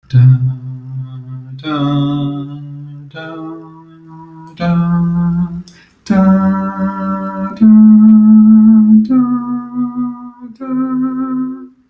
音频以单声道 16khz 采样率记录并保存为 .wav 格式。
c-scale-metronome.wav